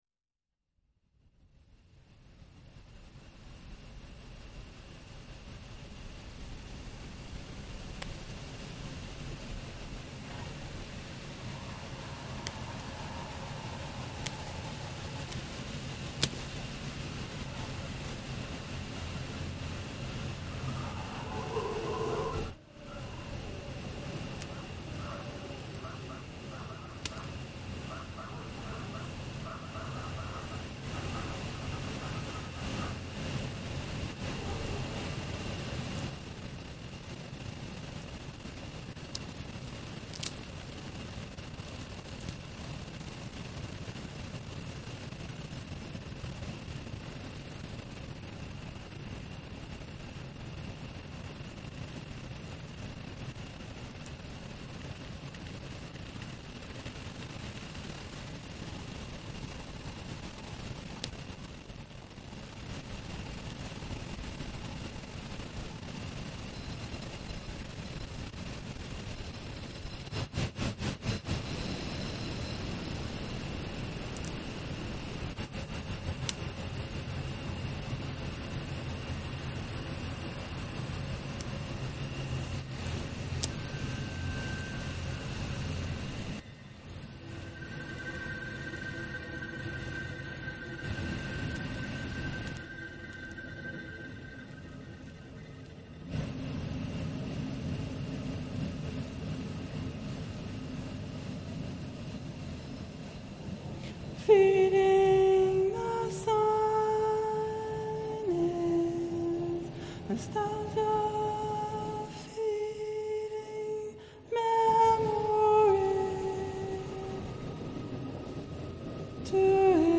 Intense and innocent as he/it is.